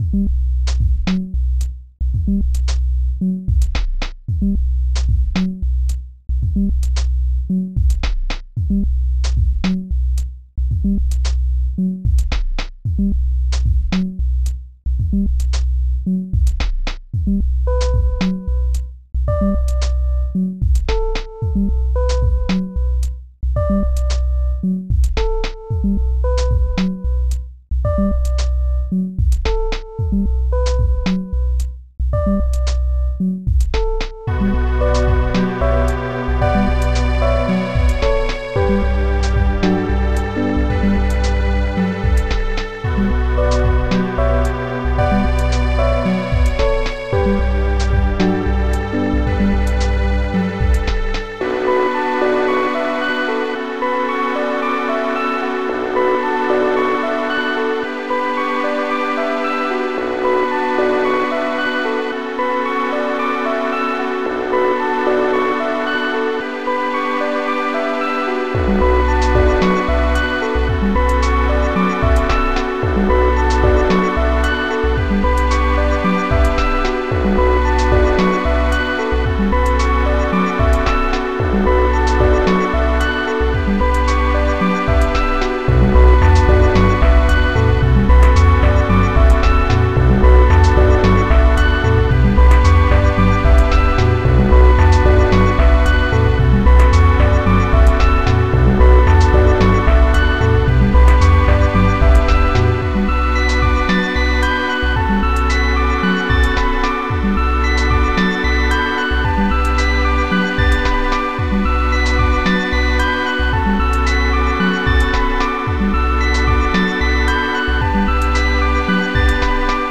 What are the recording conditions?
Type xm (FastTracker 2 v1.04) Tracker FastTracker v2.00 XM 1.04 Tracks 16 Samples 8 Patterns 17 Instruments 32k music compo